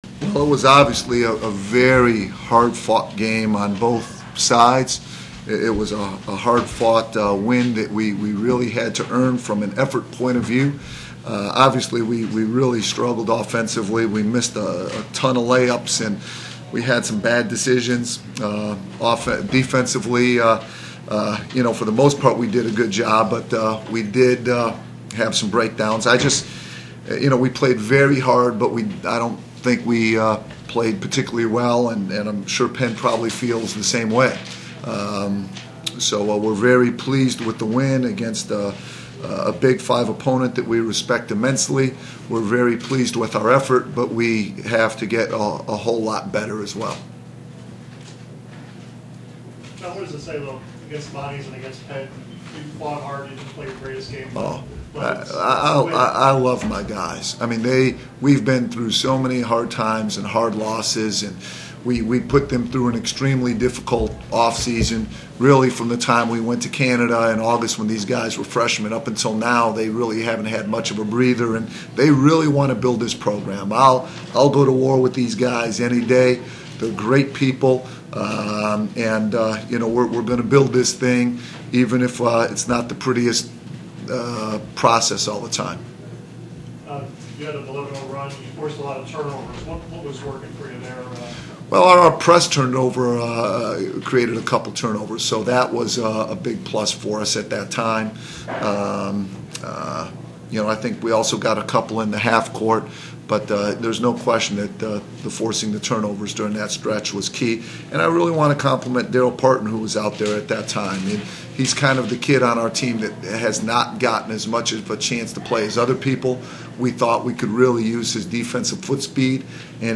Postgame audio: